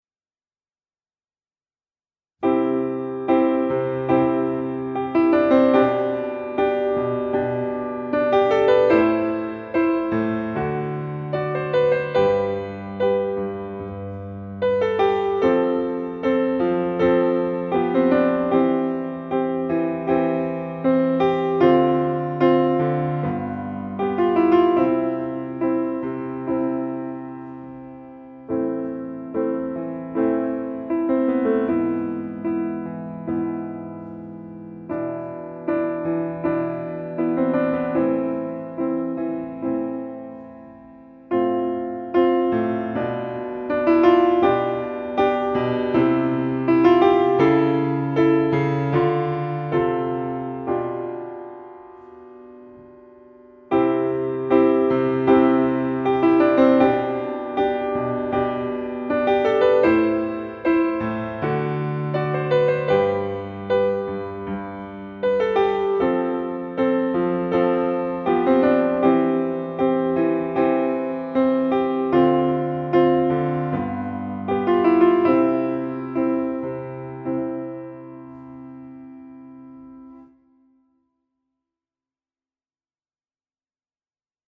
• für Klavier
Klangbeispiel